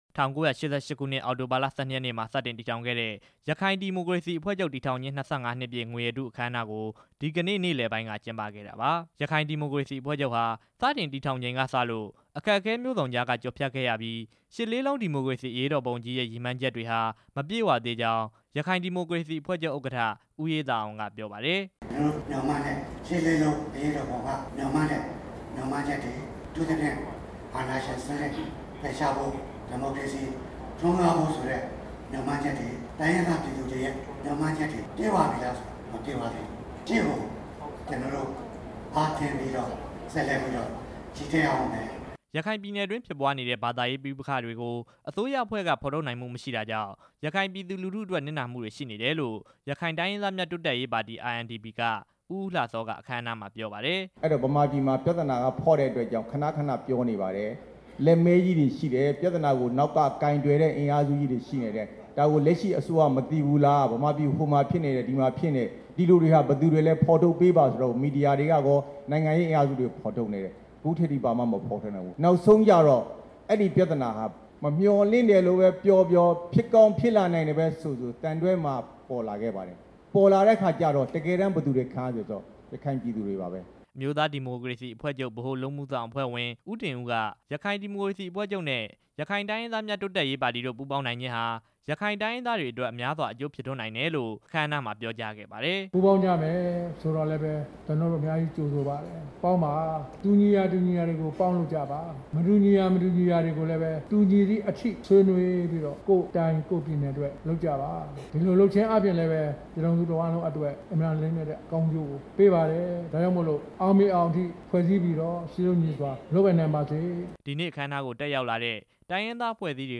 အခမ်းအနားအကြောင်း တင်ပြချက်